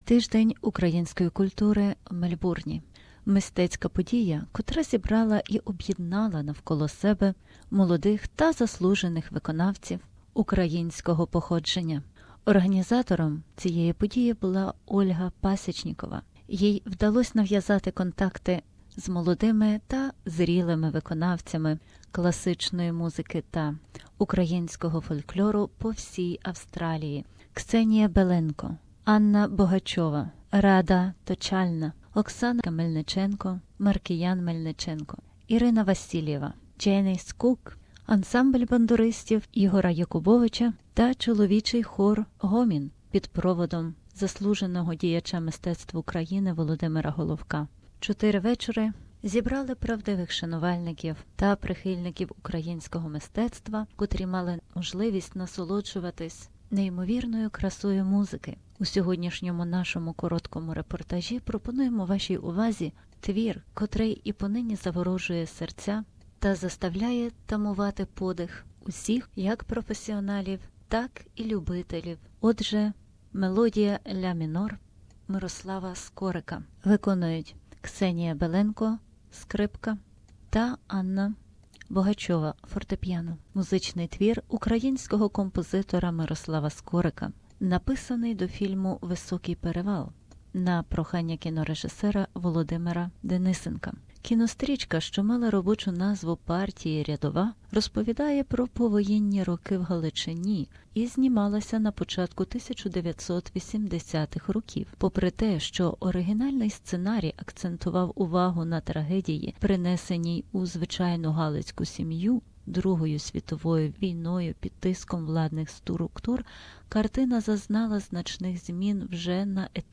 Diary : Ukrainian Cultural week in Melbourne Myroslav Skoryk - A Melody p 1